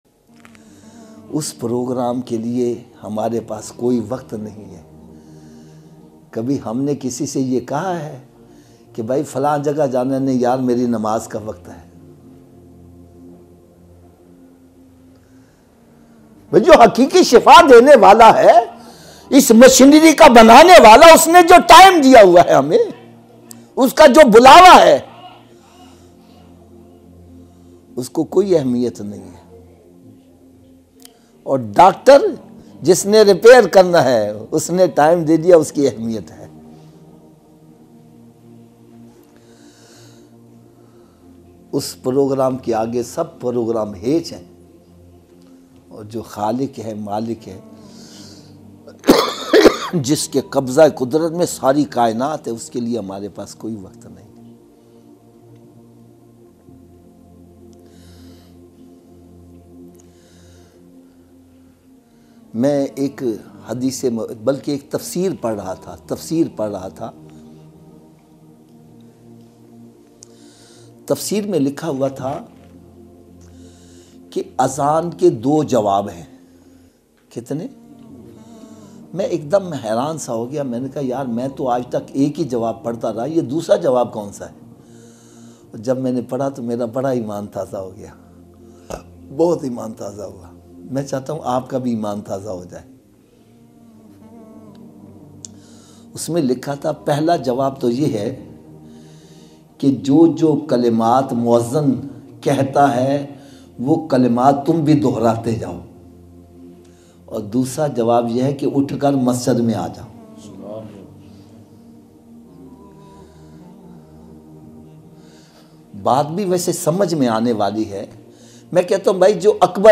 Allah Ki Zaat or Doctor ki Baat Life Changing Bayan MP3